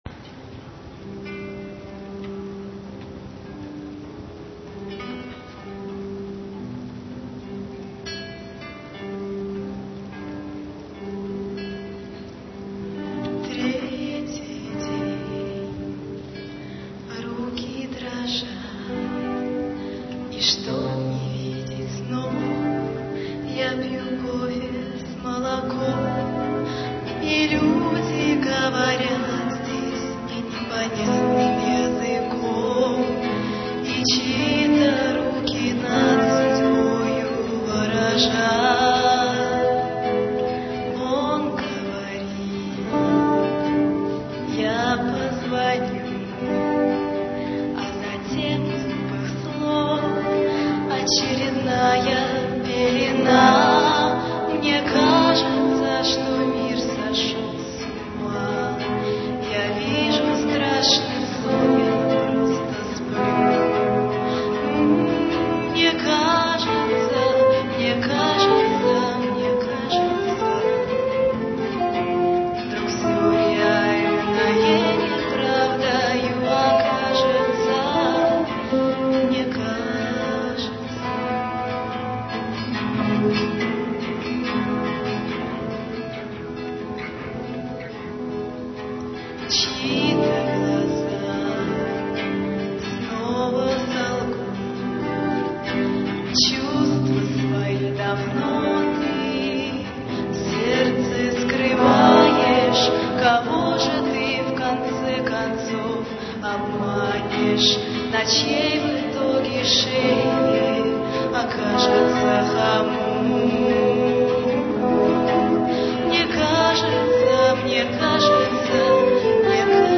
авторское исполнение, соло-гитара